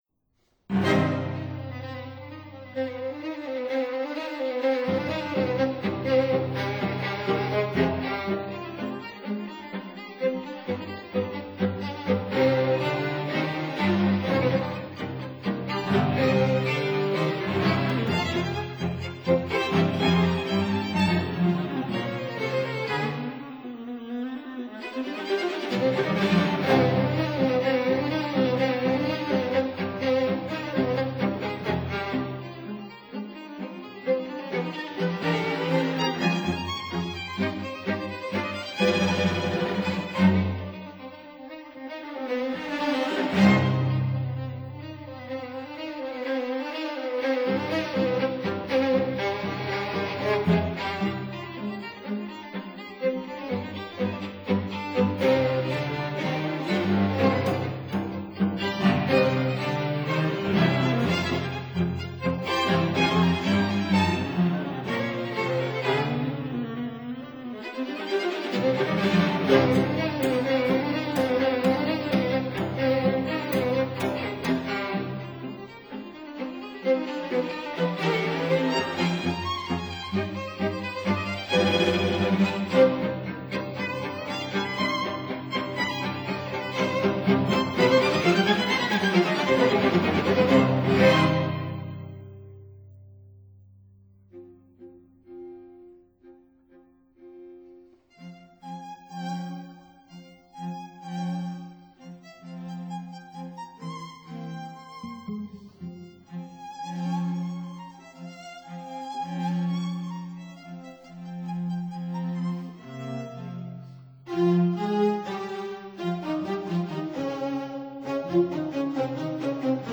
violins
viola
cello